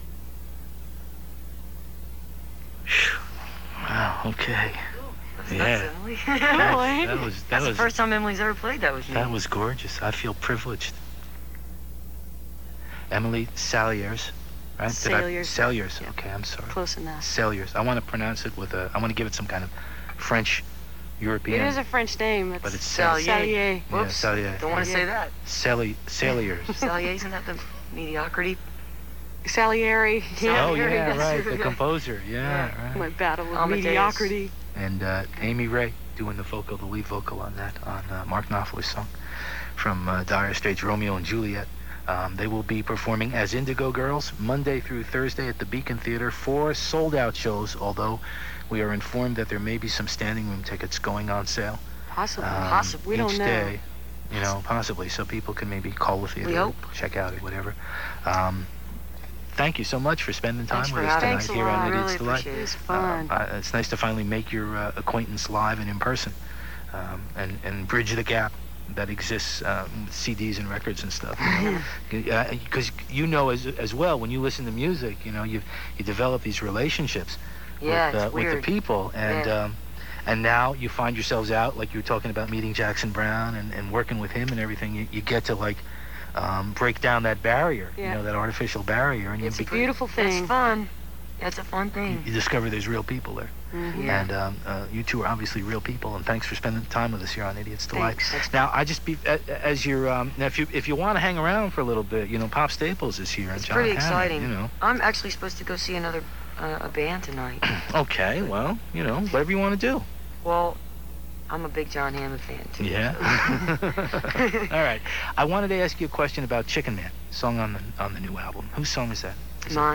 13. interview (3:13)